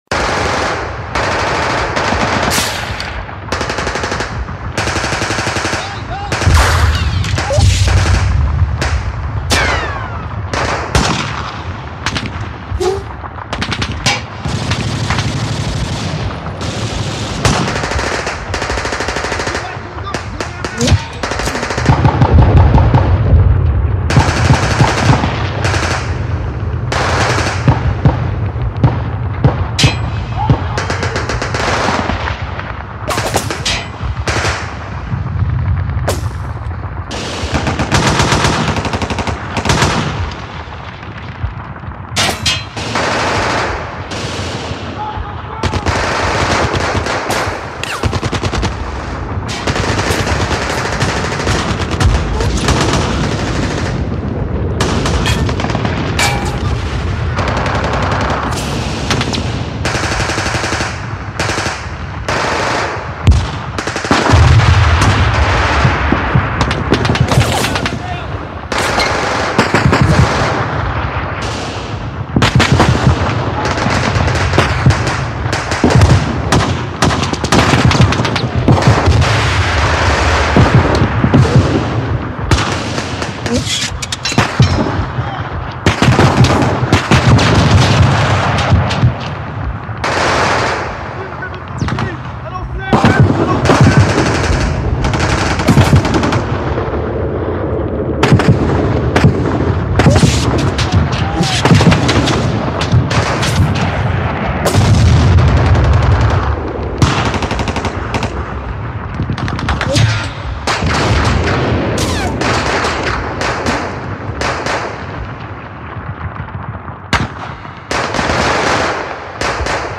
دانلود آهنگ جنگ 1 از افکت صوتی طبیعت و محیط
دانلود صدای جنگ 1 از ساعد نیوز با لینک مستقیم و کیفیت بالا
جلوه های صوتی